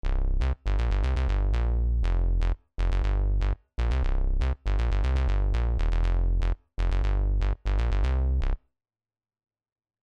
ディケイを少し遅くすることで少し音色の明るさが持続するようにしたい時には、ENVボタンを押して、エンベロープの設定を確認してみましょう。